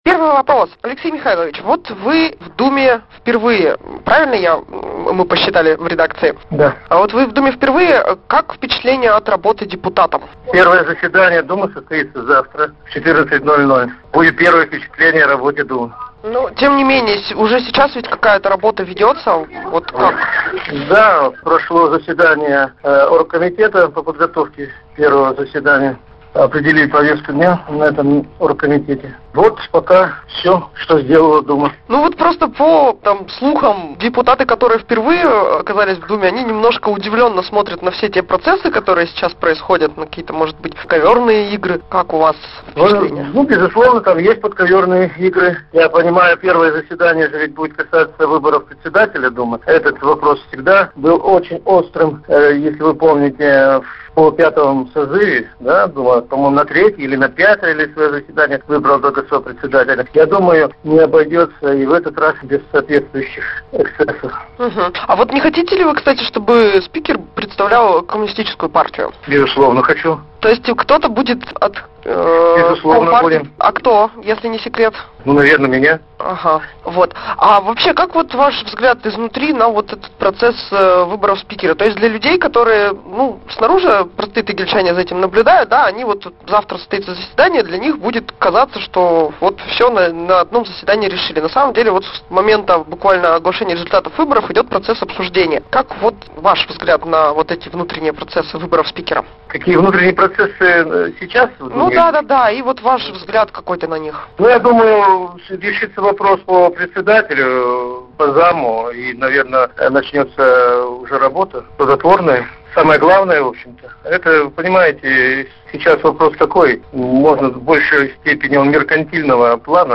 Аудиовариант интервью:
Депутат городской Думы